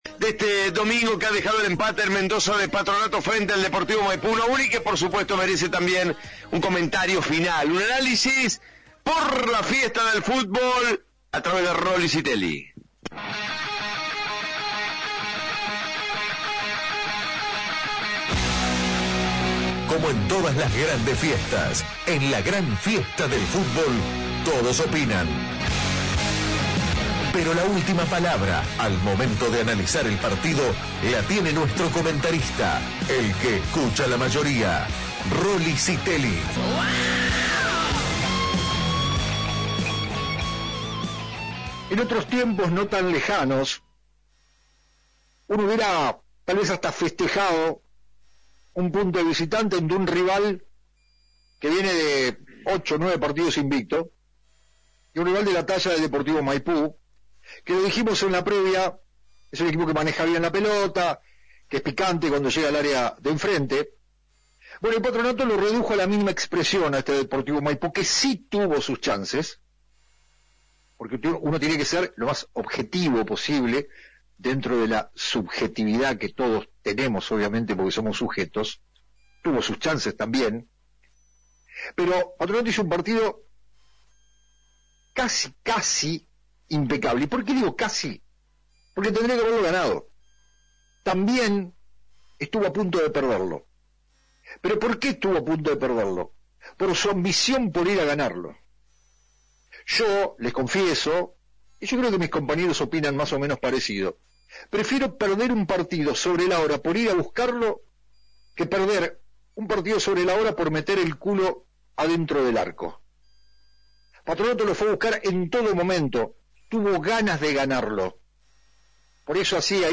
(Comentarista de «La Fiesta del Fútbol»)